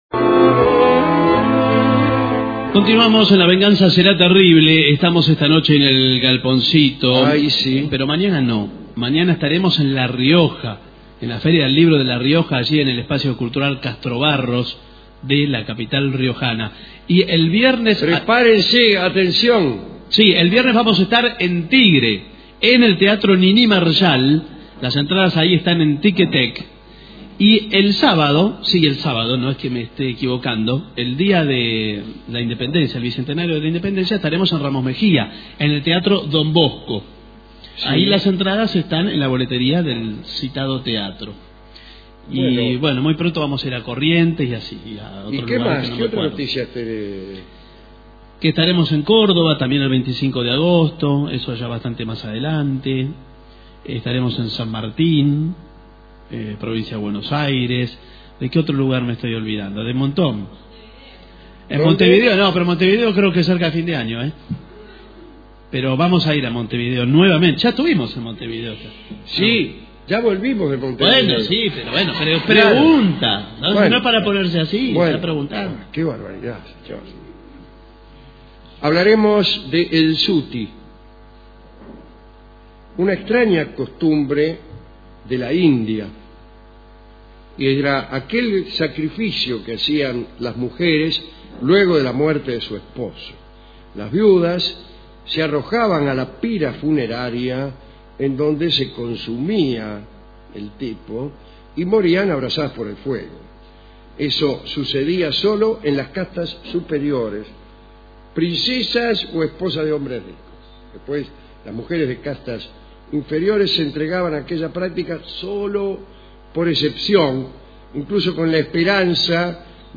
Estudios de Radio Rivadavia (AM 630 kHz), 1989